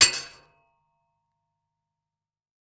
sfx_impact_fence.wav